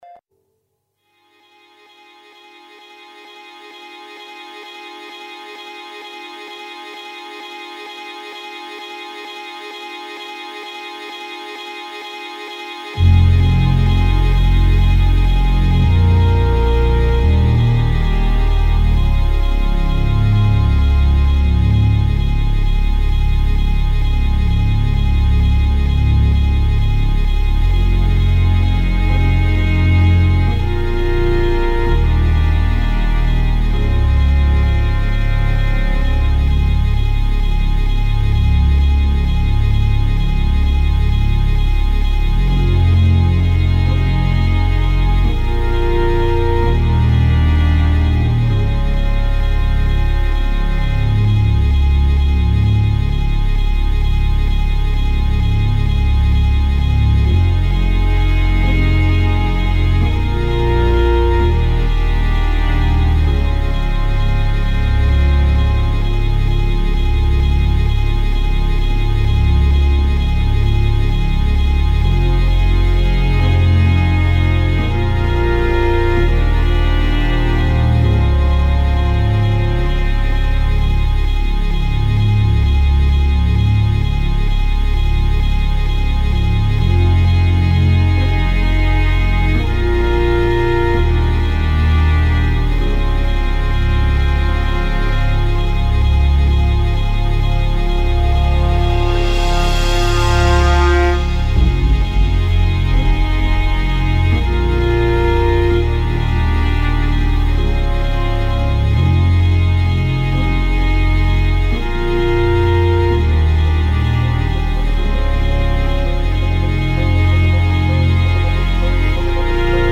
Musica a 360°, viva, legata e slegata dagli accadimenti.